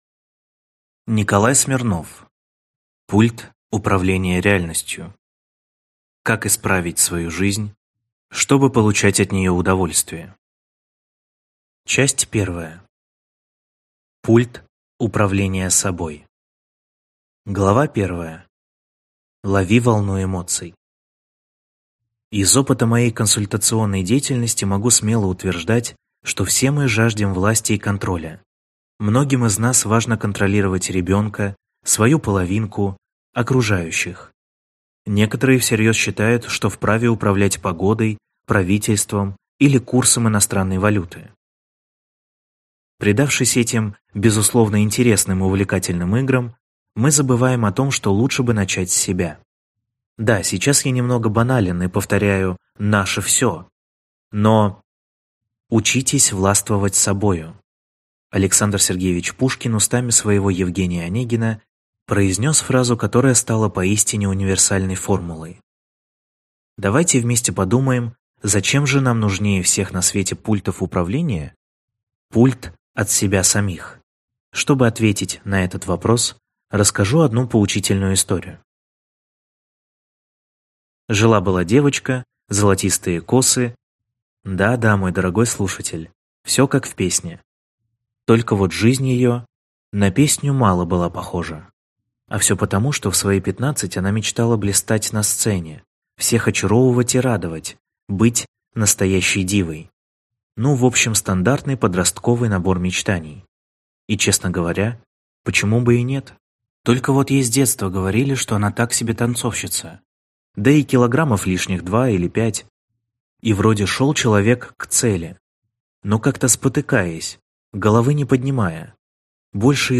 Аудиокнига Пульт управления реальностью. Как исправить свою жизнь, чтобы получать от нее удовольствие | Библиотека аудиокниг